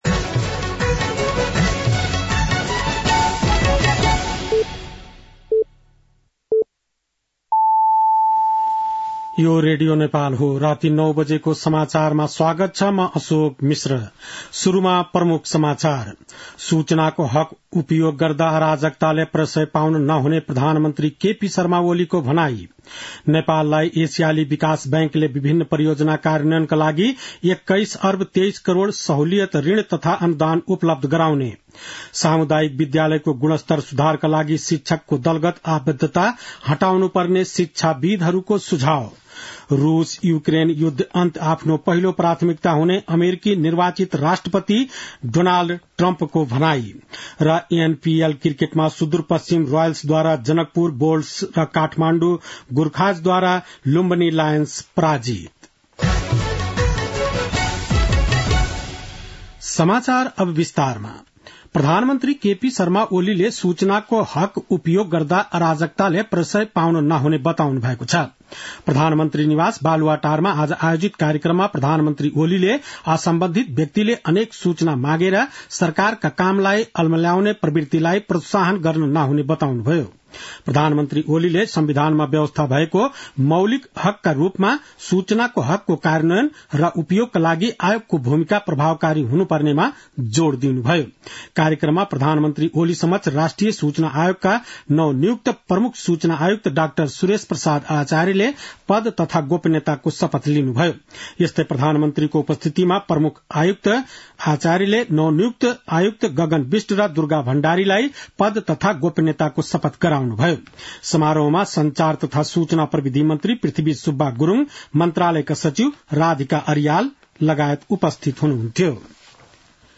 बेलुकी ९ बजेको नेपाली समाचार : २७ मंसिर , २०८१